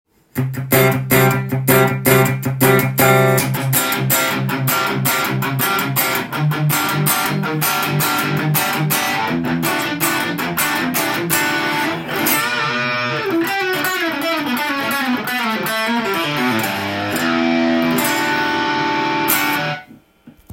チューブアンプのガラスようなザクザクした音色が楽しめる
まさにその通りのギターサウンドに仕上がっています。